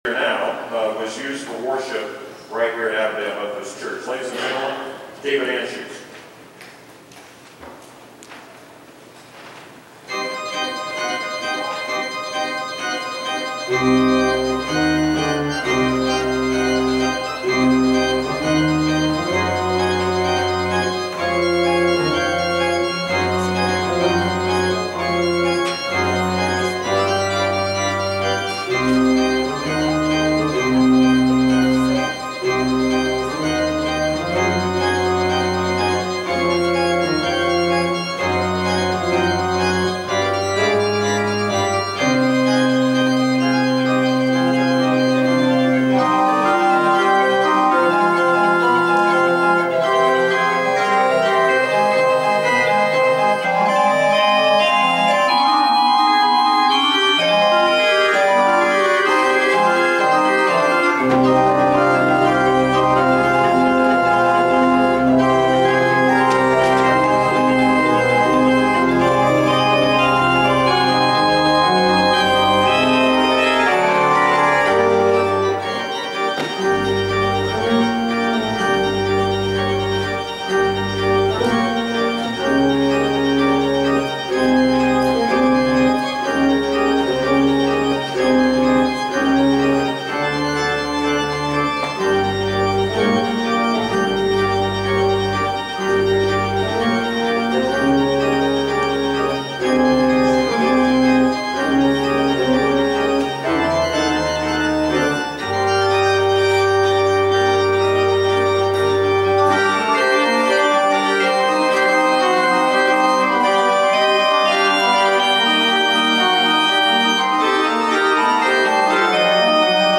Following the Worship Service and the Anniversary Dinner, the day’s events Sunday concluded with a Music Celebration service.
That was the same postlude he played in 1979 when the church organ was new to the church.